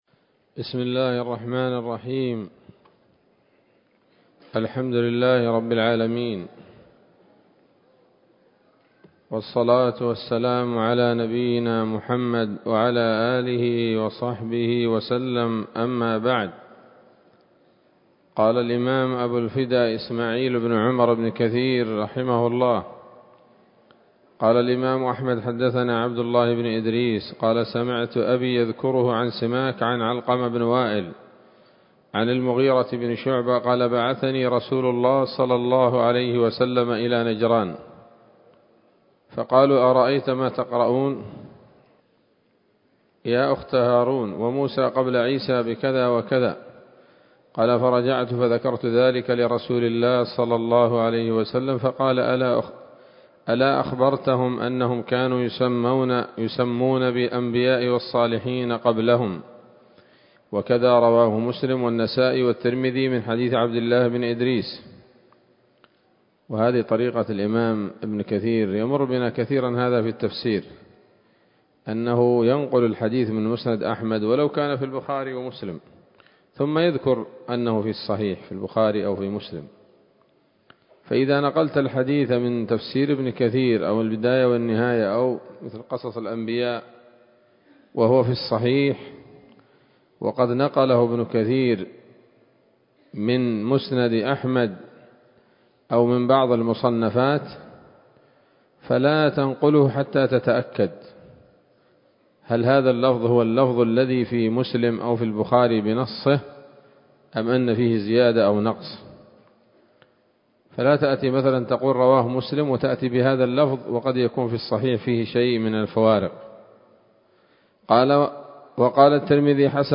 ‌‌الدرس الثالث والأربعون بعد المائة من قصص الأنبياء لابن كثير رحمه الله تعالى